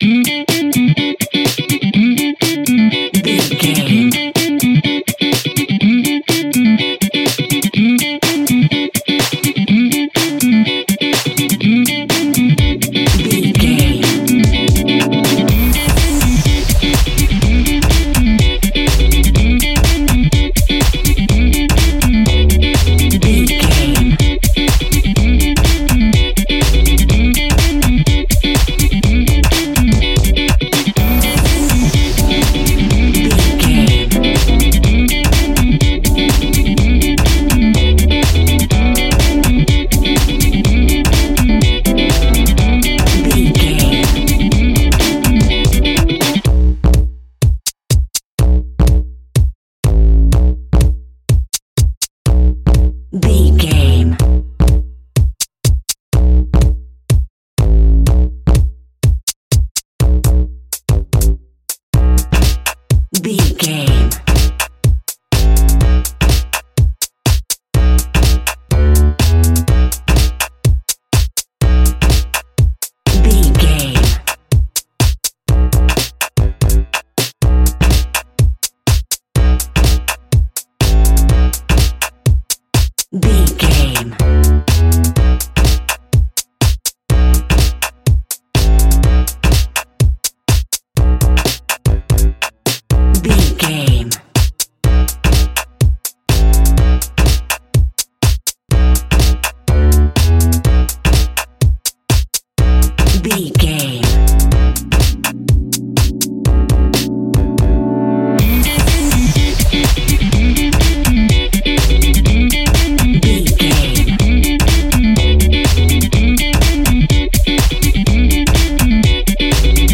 Aeolian/Minor
groovy
uplifting
driving
energetic
bass guitar
synthesiser
electric guitar
drums
piano
disco
nu disco
instrumentals